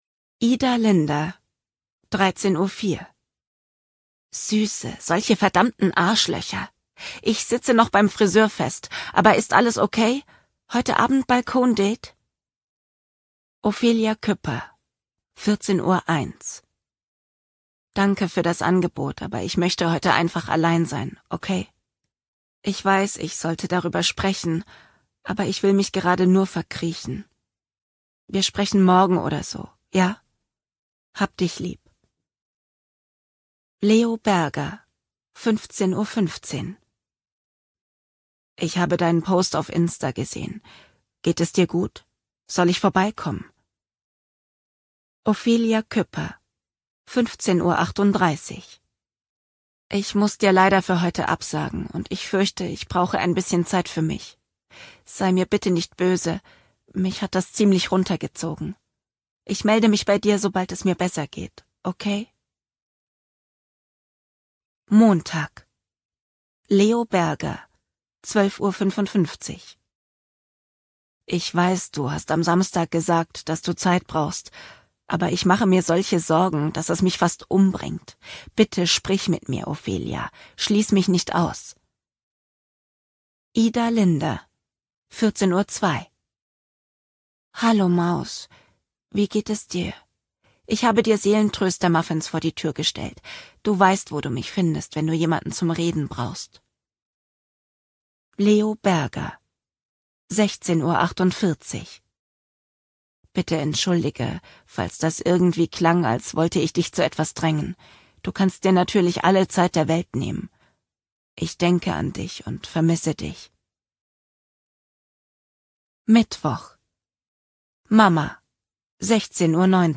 Hörprobe New Adult | aus: Marie Weis: “To my sunflower” (2024)
Wenn ich Hörbücher spreche